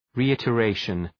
Shkrimi fonetik{ri:,ıtə’reıʃən}
reiteration.mp3